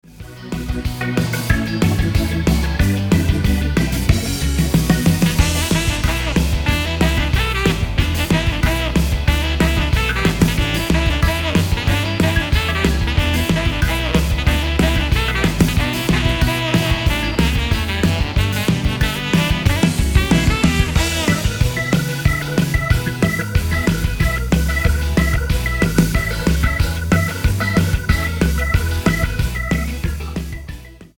185 BPM